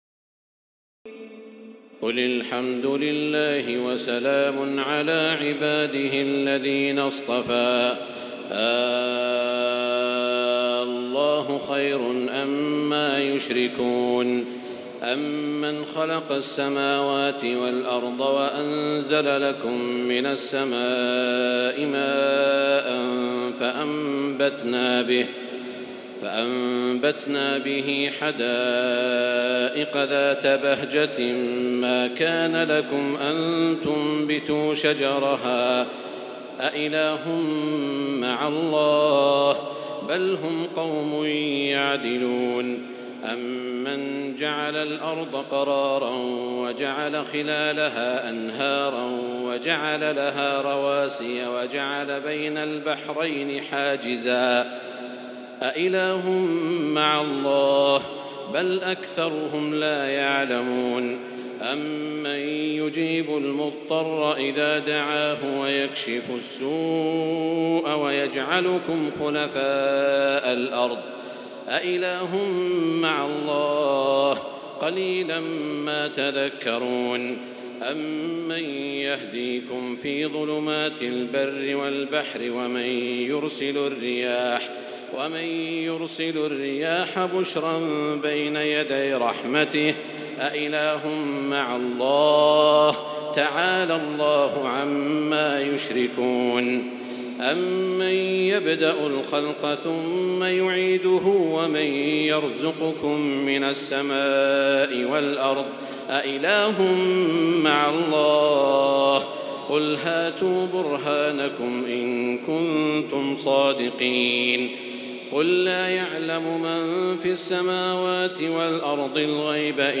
تراويح الليلة التاسعة عشر رمضان 1438هـ من سورتي النمل(59-93) و القصص(1-50) Taraweeh 19 st night Ramadan 1438H from Surah An-Naml and Al-Qasas > تراويح الحرم المكي عام 1438 🕋 > التراويح - تلاوات الحرمين